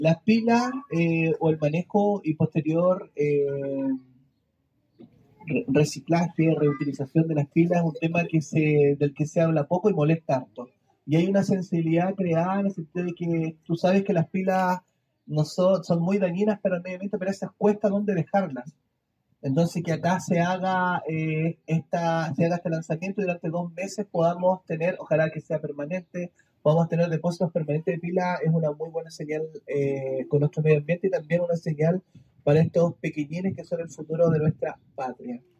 La actividad se realizó en el jardín infantil “Raíces de mi pueblo” en la comuna de Paillaco.
Audio-Alcalde-de-Paillaco-Miguel-Angel-Carrasco.mp3